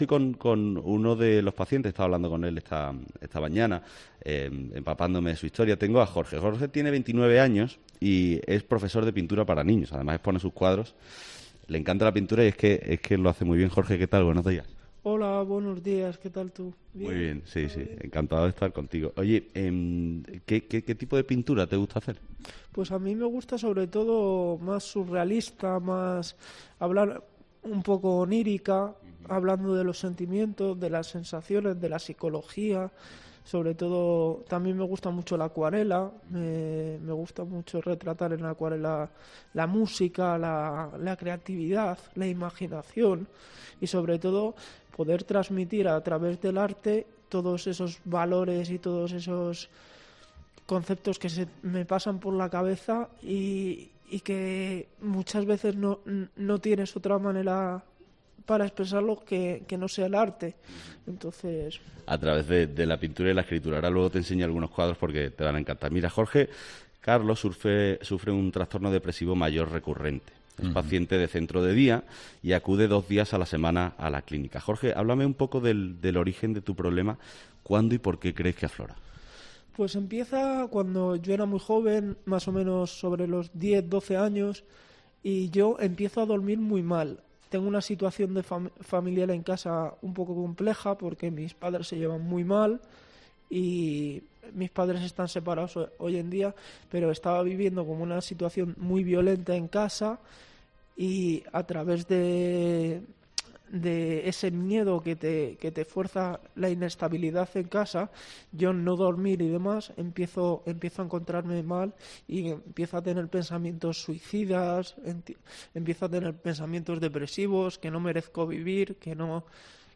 Desde las 9 de la mañana, los comunicadores recorren distintos puntos de España para arrojar luz ante los problemas de salud mental. La primera parada es la Clínica Nuestra Señora de La Paz, de la orden San Juan de Dios, en Madrid, un centro especializado en salud mental, hasta donde llegan pacientes desde todos los puntos del país.